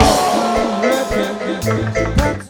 DUBLOOP 03-R.wav